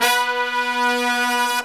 LONG HIT02-L.wav